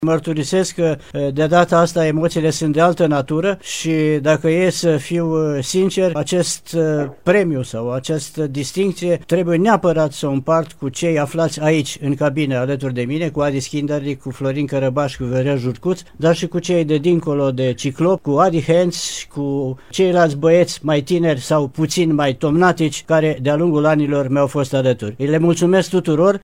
Invitat special atunci în platou